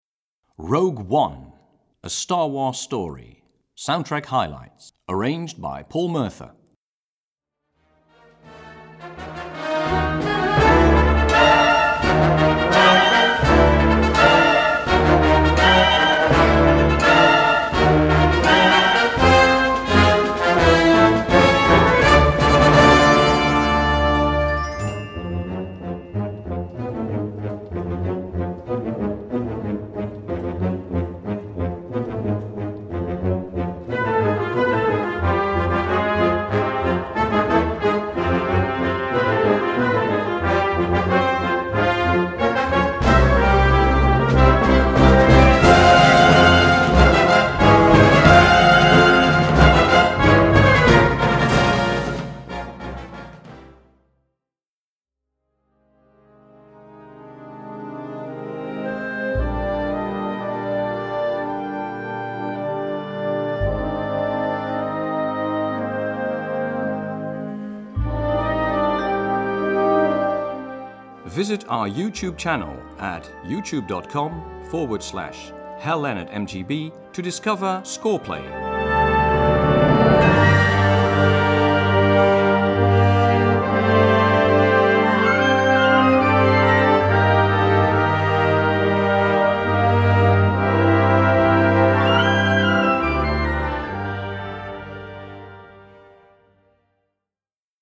Gattung: Filmmusik
Besetzung: Blasorchester
Mitreißende Musik für Blasorchester.